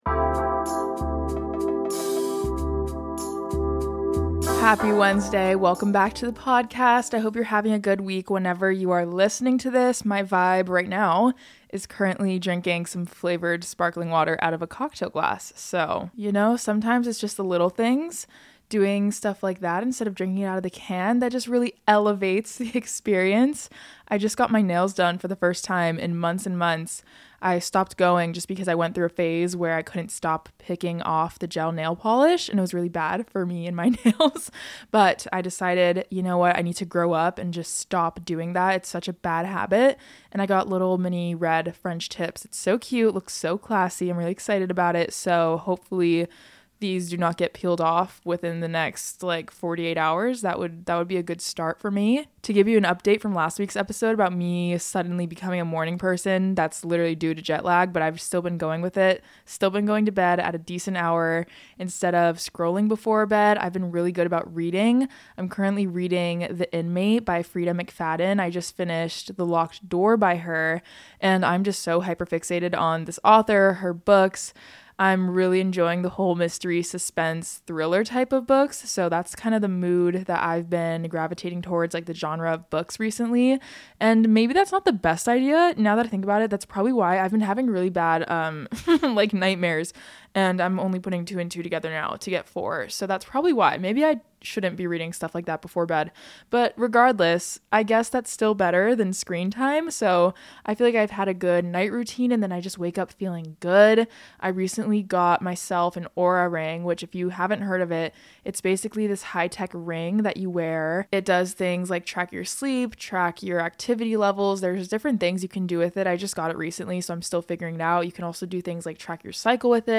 Buckle up, today I'm reading your stories about the biggest lie you've ever told!